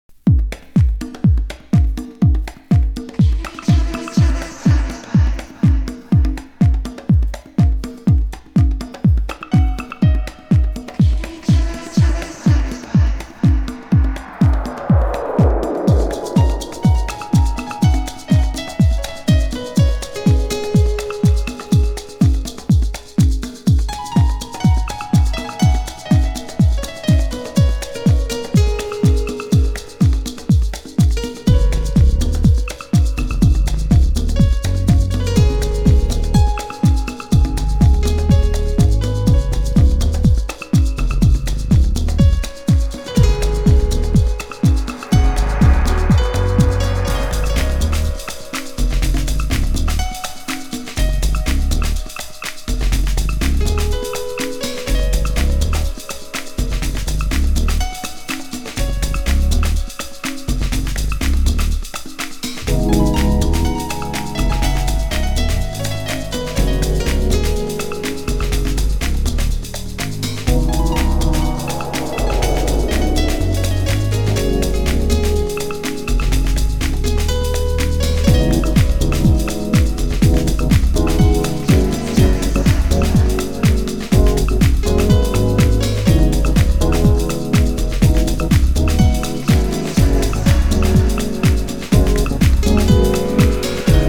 HOUSE MUSIC
盤質：イントロ部に少しチリパチノイズ有　　ジャケ：無地スリーブに少しシミ汚れ有